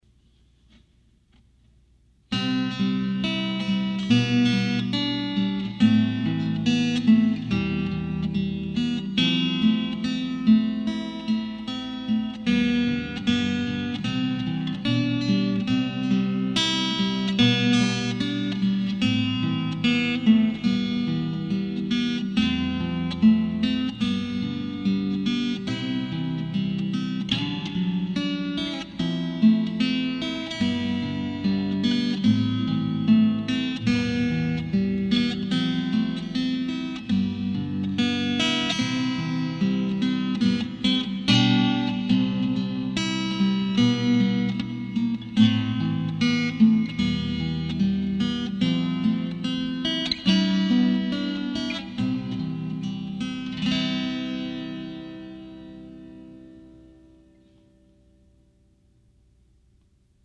中古で安く手にはいる、マルチエフェクタで録音してみます。
一弦側ブリッジ下にピックアップを１つ貼り付け
ということで、今回はステレオ録音
※あまりにノイズが多いので
多分、PCのマイクがミュートになってない・・（笑）